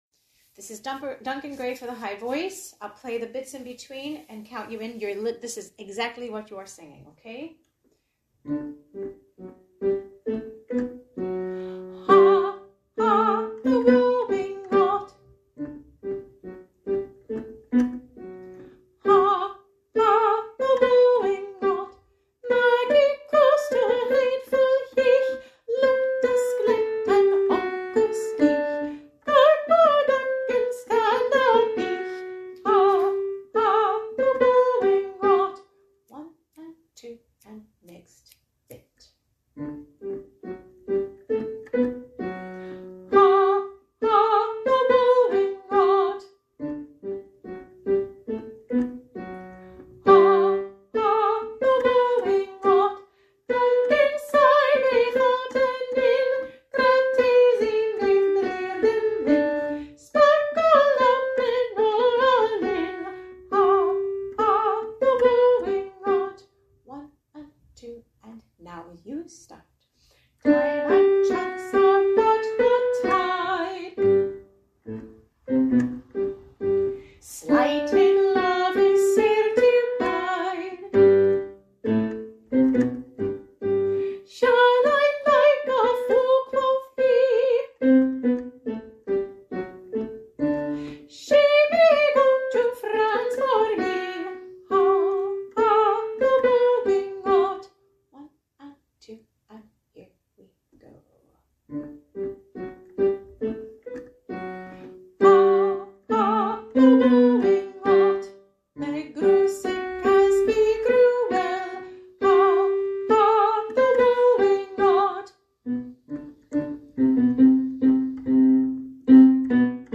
Sing Along Tracks (mp3):High VoiceMiddle VoiceLow Voice
High Voice